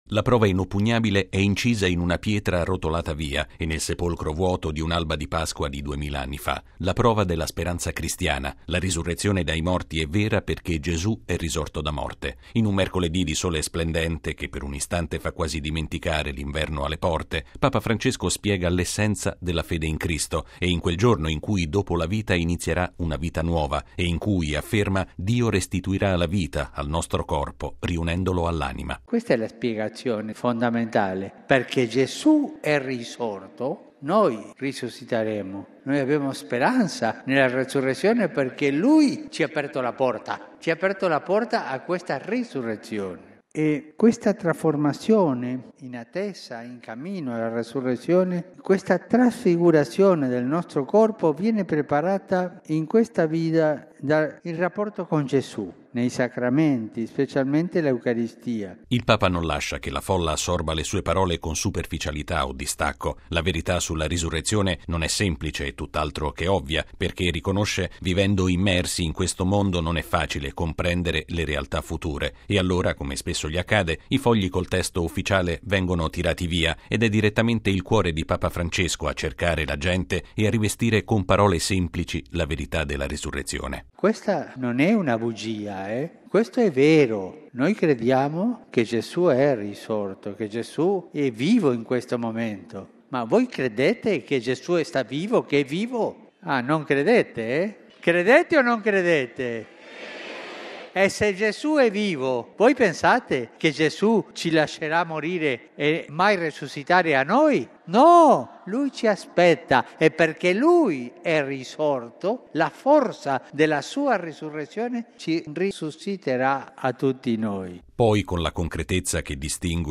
Con questa affermazione, più volte ribadita e condivisa con le circa 30 mila persone presenti in Piazza San Pietro, Papa Francesco ha affrontato all’udienza generale di questa mattina una delle realtà ultime della fede, professata nel Credo: la “risurrezione della carne”. Credere nella risurrezione, ha aggiunto, aiuta a essere “meno prigionieri dell’effimero” e più disposti alla misericordia.